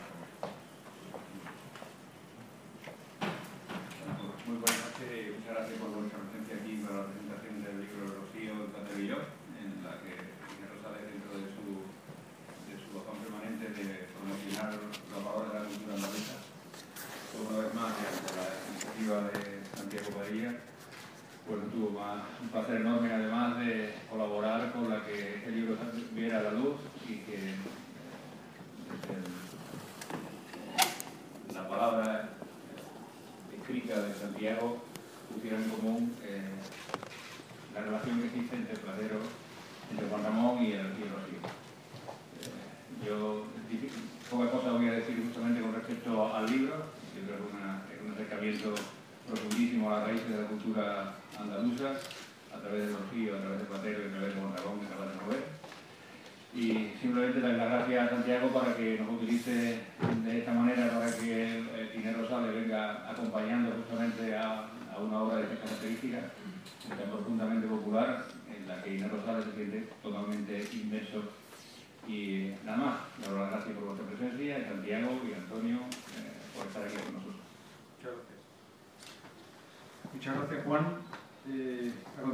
El público, que llenó completamente la sala, disfrutó con las diferentes intervenciones de la noche.
Presentación a cargo de: